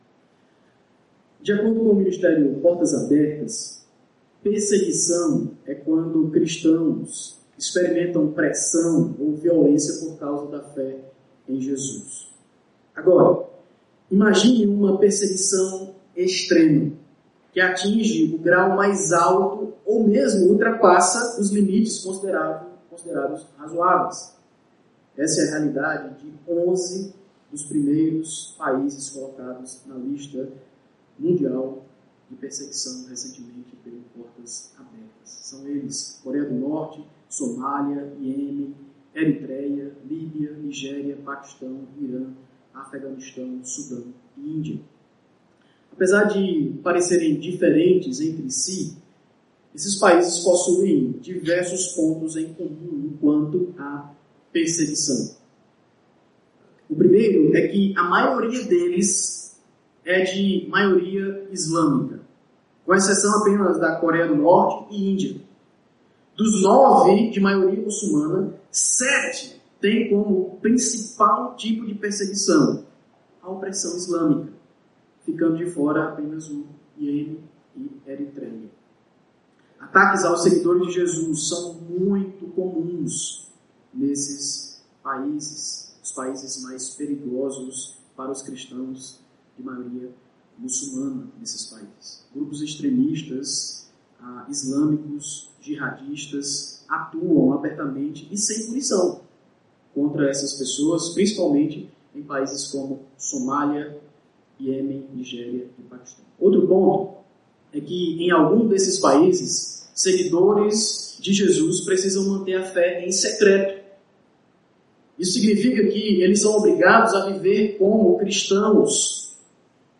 Igreja Batista Luz do Mundo, Fortaleza/CE.
pregação